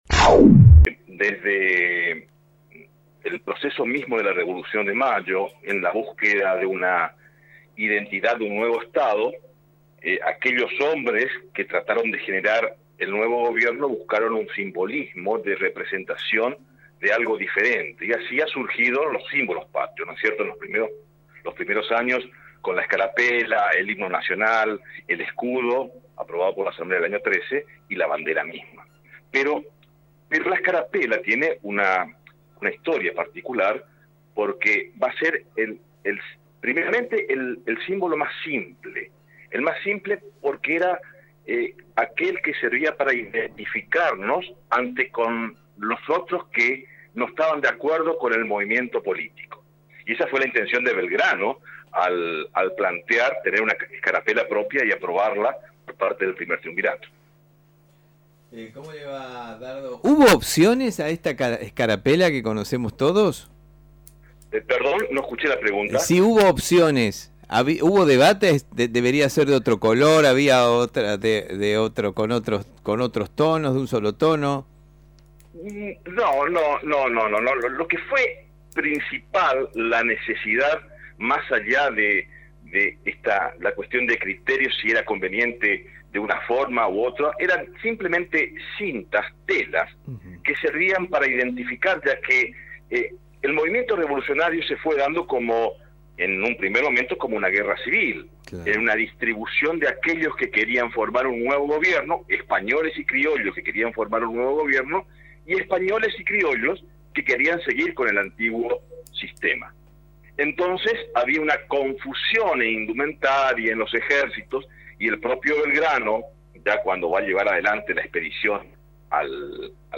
AM 980